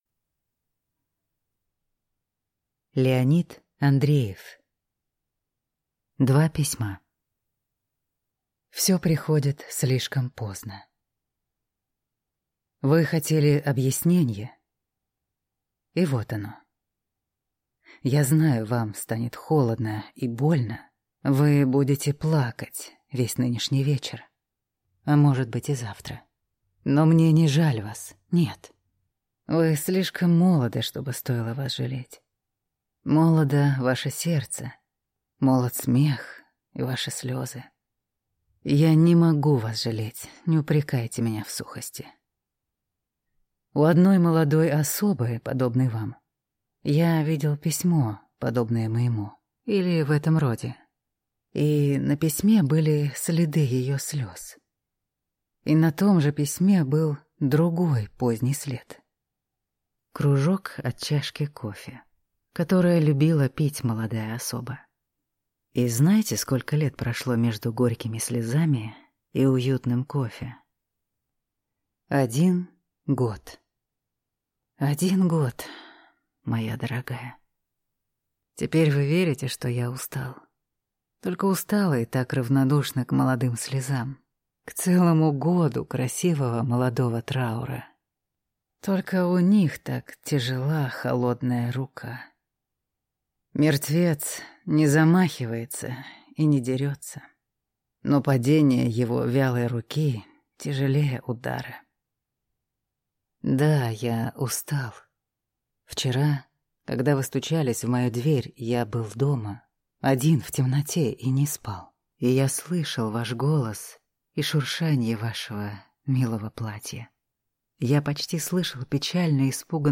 Аудиокнига Два письма | Библиотека аудиокниг